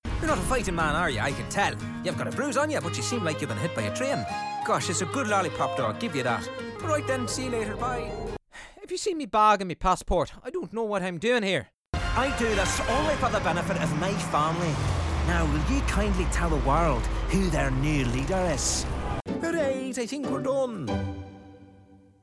irish | character
Irish_samples__1.mp3